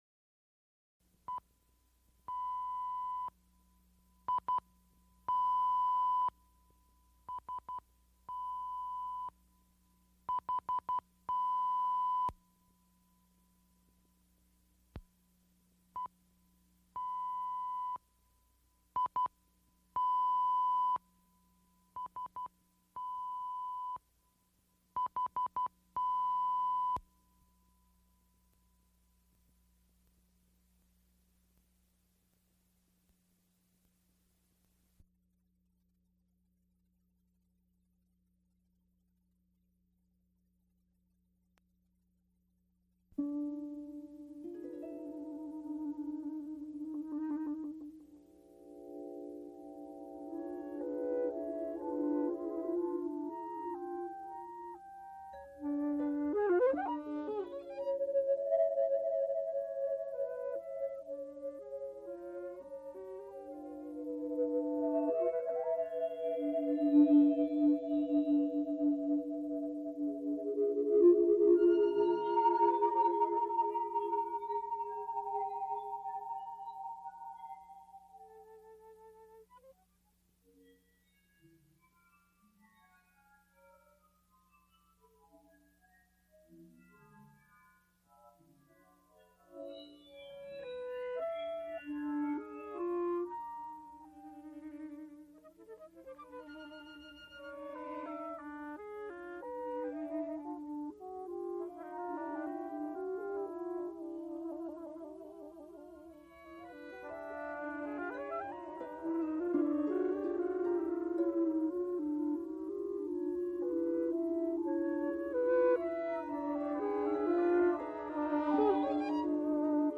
A Concert of computer and electronic music | Digital Pitt
Recorded June 26, 1980, Frick Fine Arts Auditorium, University of Pittsburgh.
Extent 2 audiotape reels : analog, half track, 15 ips ; 12 in.
Computer music Electronic music Trombone and electronic music